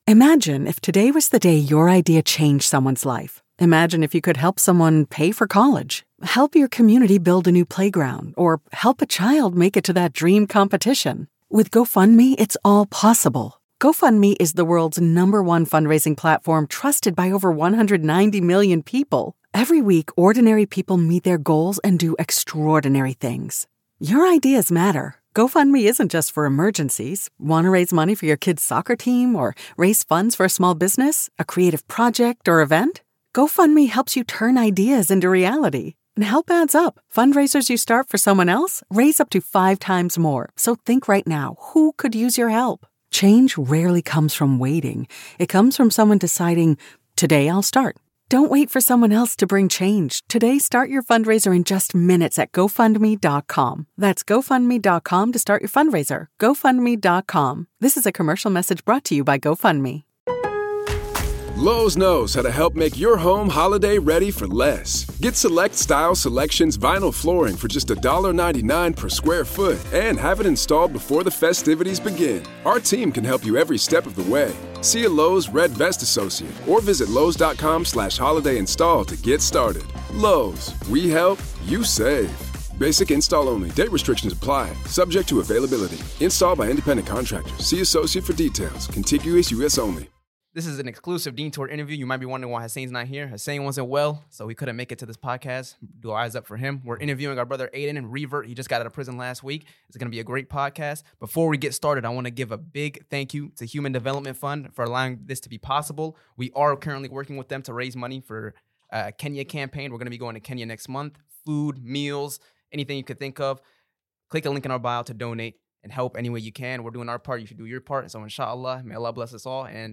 In this podcast we bring on a revert brother of ours who spent sometime behind bars as part of a test from Allah. This brother was on one of the earliest episodes of the podcast and we finally get to speak with him face to face about his experiences and how it brought him closer to his faith.